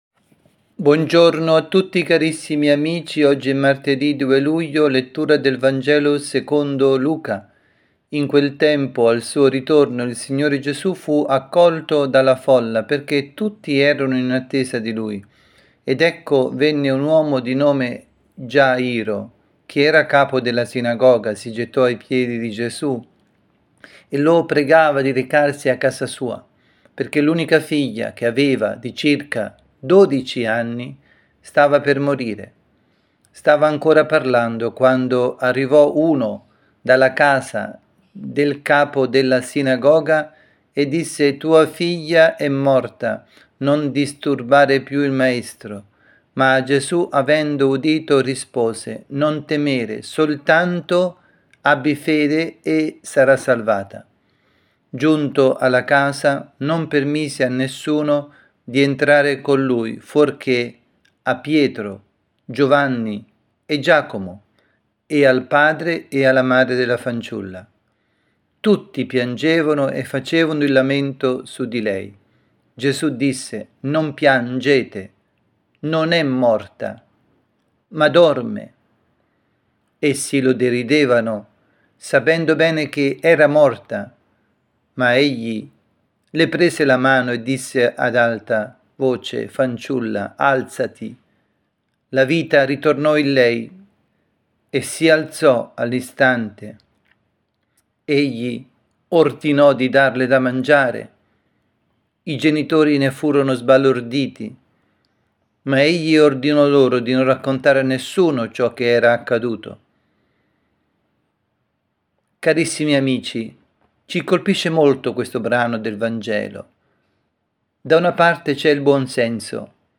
avvisi, Catechesi, Omelie
dalla Parrocchia Santa Rita – Milano